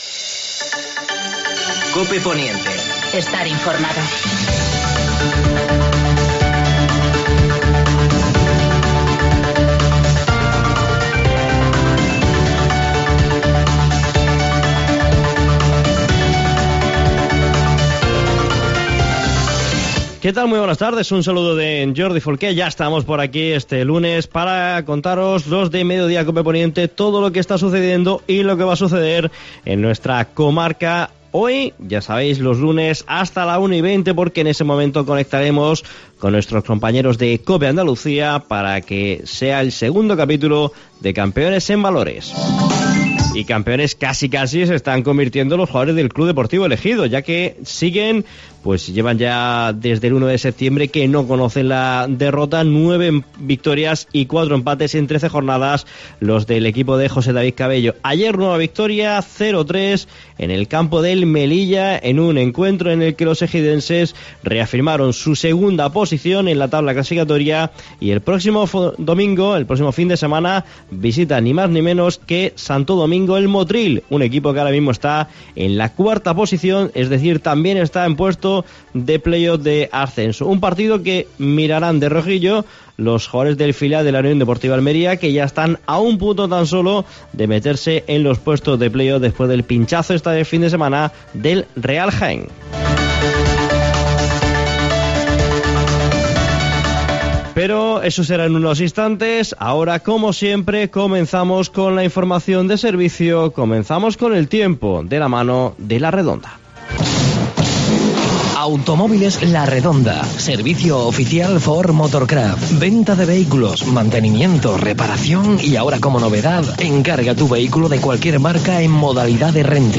También hemos hablado del Plan de Caminos Agrícolas y/o Ganaderos 2020/2021 que ha presentado la Diputación Provincial en El Ejido. Escuchamos al alcalde ejidense (Francisco Góngora) y al presidente de la Diputación (Javier Aureliano García).